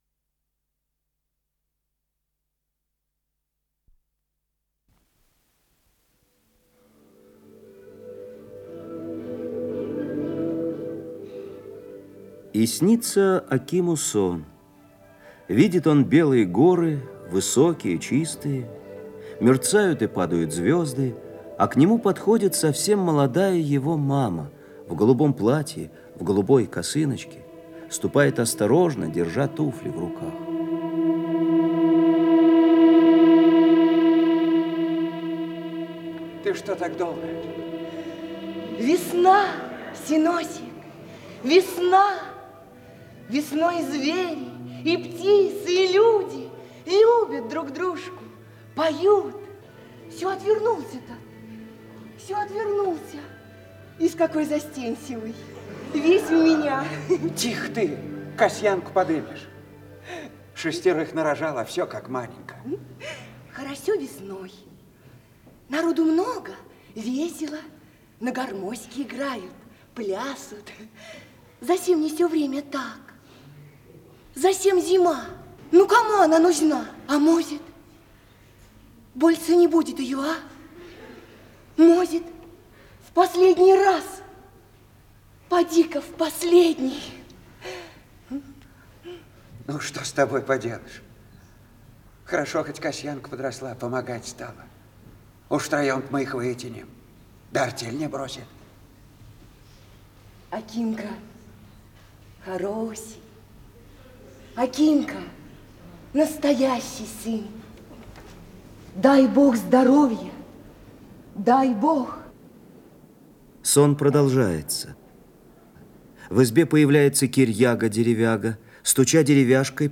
ведущий Артисты Казанского русского Большого драматического театра им. Качалова